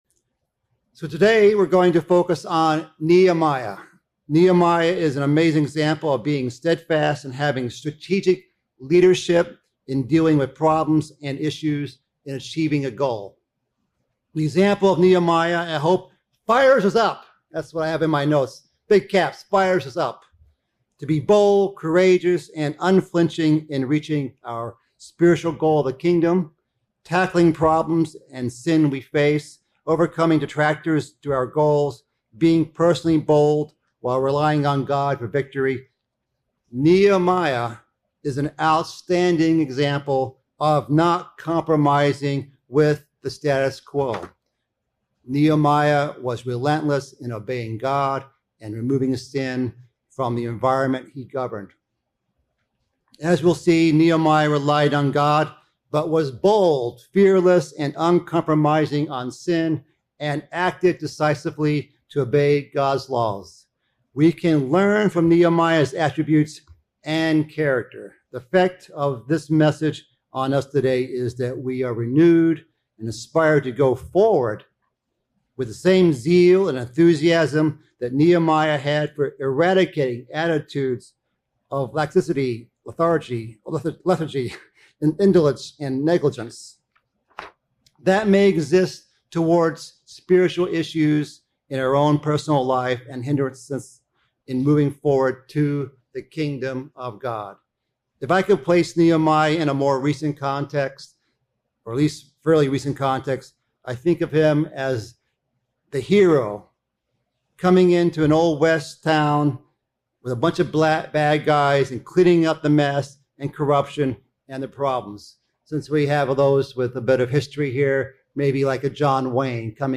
A Fired-Up Nehemiah Sermon